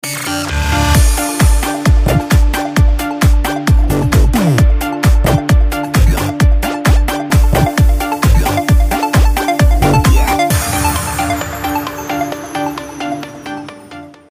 رینگتون با انرژی و بیکلام